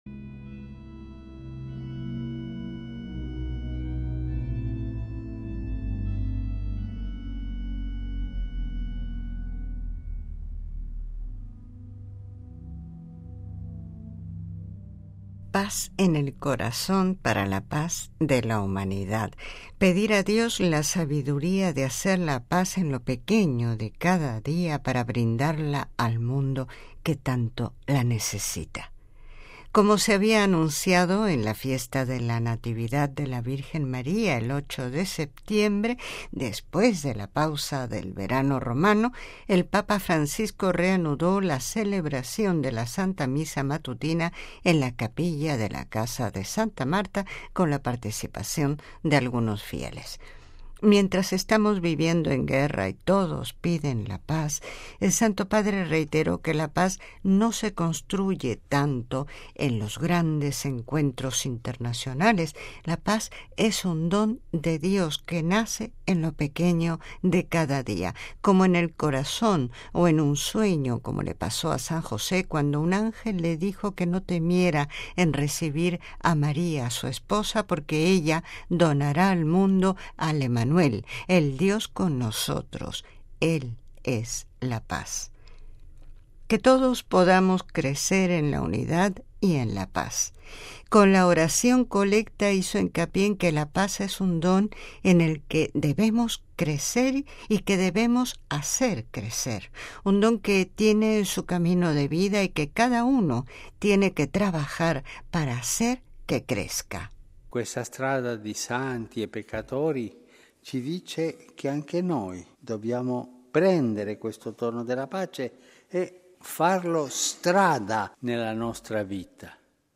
Homilía del Papa: paz en el corazón, para la paz de la humanidad
(RV).- Como se había anunciado, en la fiesta de la Natividad de la Virgen María, el 8 de septiembre, después de la pausa del verano romano, el Papa Francisco reanudó la celebración de la Santa Misa matutina, en la Capilla de la Casa de Santa Marta, con la participación de algunos fieles.